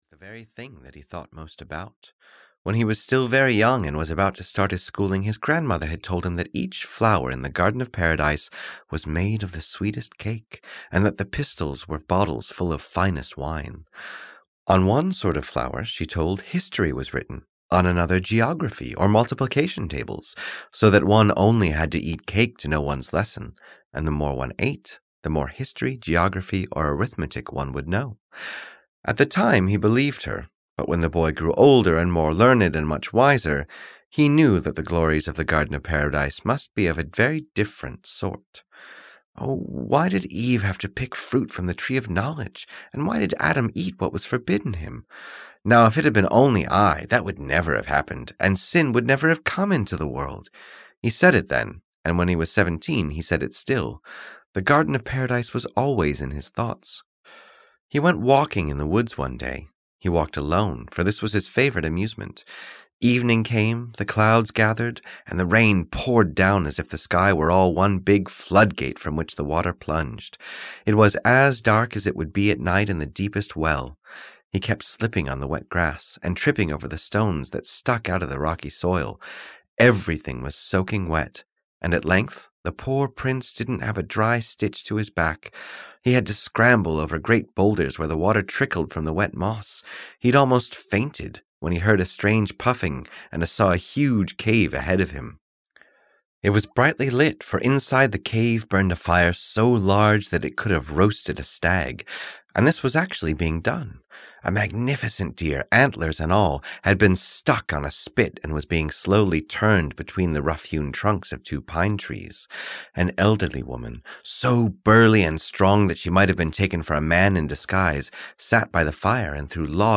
The Garden of Paradise (EN) audiokniha
Ukázka z knihy